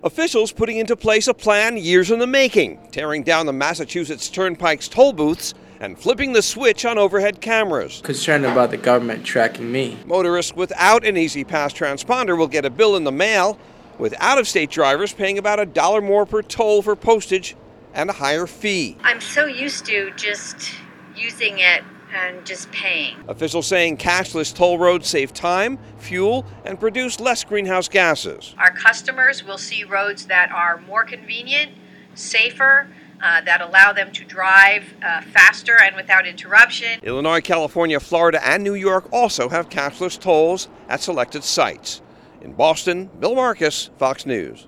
(BOSTON) OCT 29 – TODAY (SATURDAY) IS THE FIRST FULL DAY OF ELECTRONIC TOLLING ON THE MASSACHUSETTS TURNPIKE. FOX NEWS RADIO’S